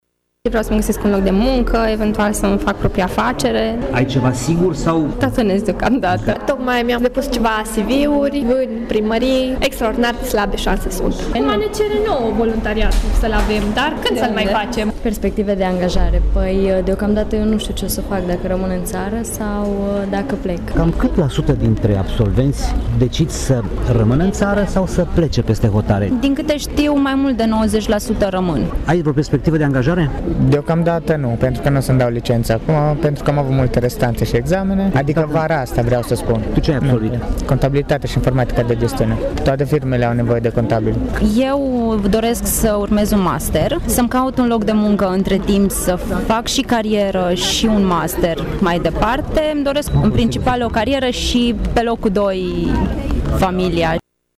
Evenimentul a avut loc la Sala Polivalentă din Tîrgu-Mureș, unde cei 1212 absolvenți la licență, masterat și doctorat au fost sărbătoriți de conducerea univesității, rude și prieteni.